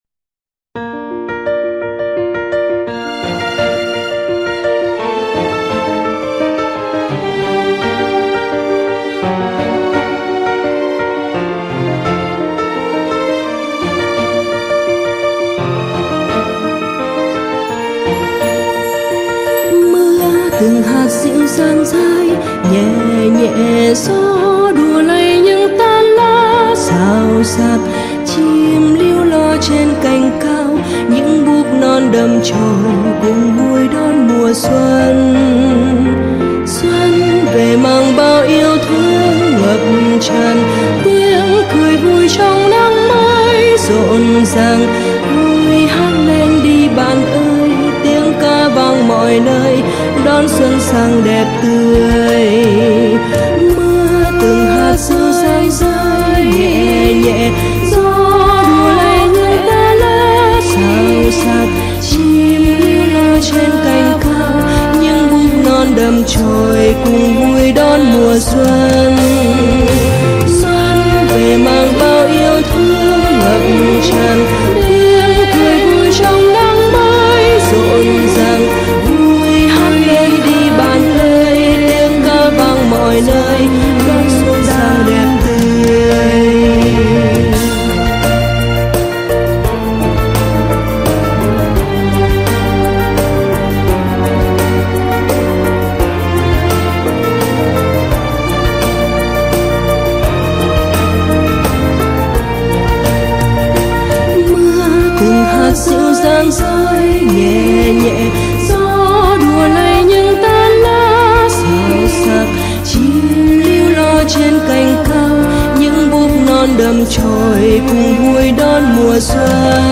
KHÚC CA CHÀO XUÂN / Minuet in G Major - J.S.Bach (Hát mẫu): Chủ đề 4 SGK Âm nhạc 8 Cánh diều